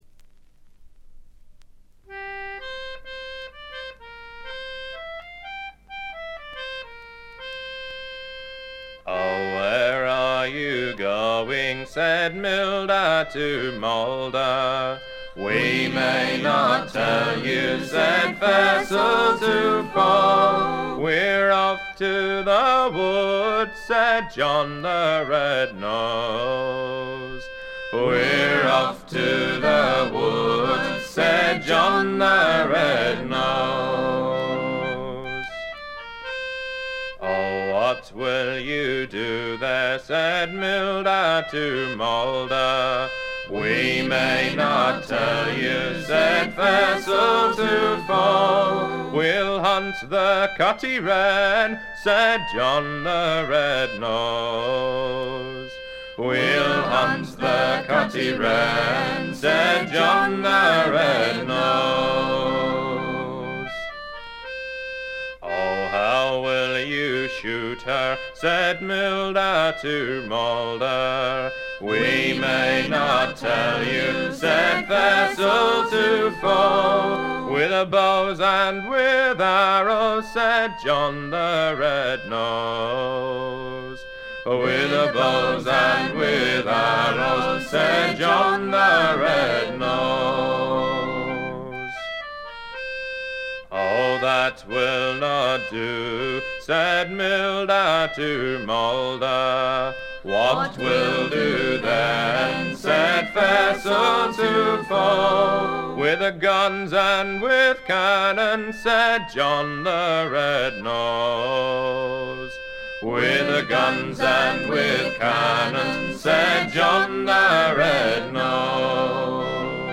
軽微なバックグラウンドノイズのみ。
試聴曲は現品からの取り込み音源です。